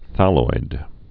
(thăloid) also thal·loi·dal (thə-loidl)